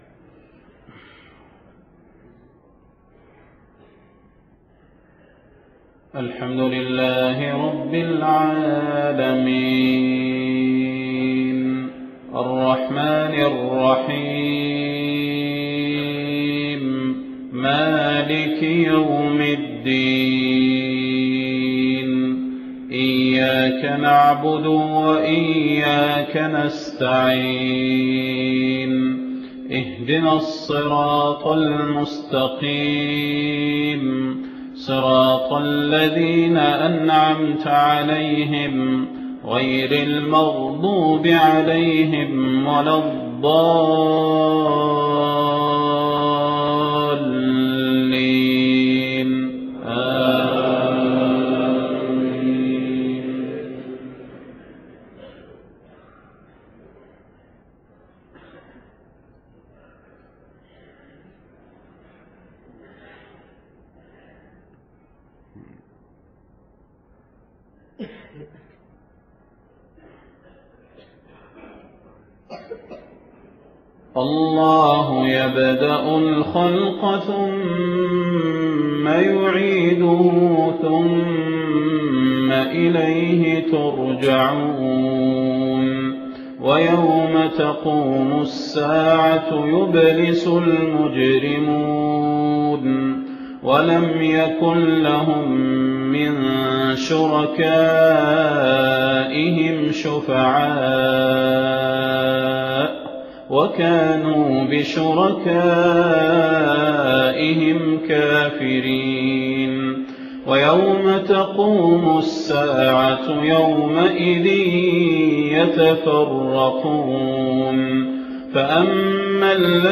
صلاة الفجر 10 محرم 1430هـ من سورة الروم 11-27 > 1430 🕌 > الفروض - تلاوات الحرمين